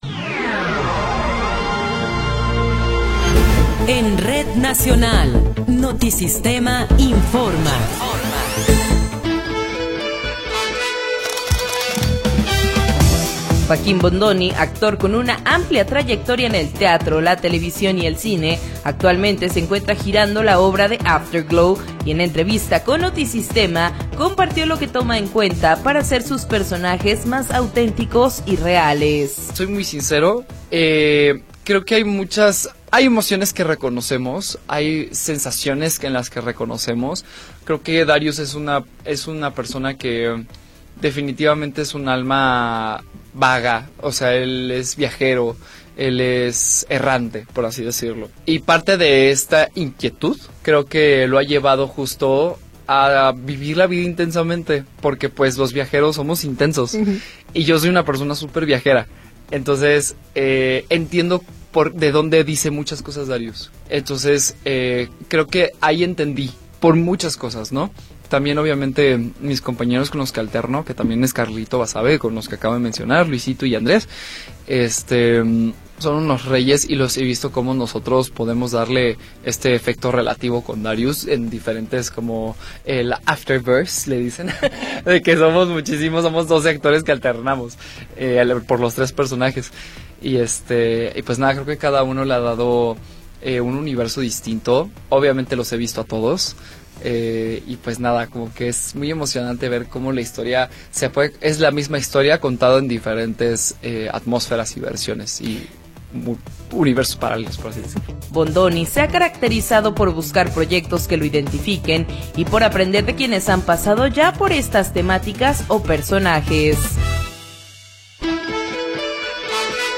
Noticiero 19 hrs. – 26 de Abril de 2026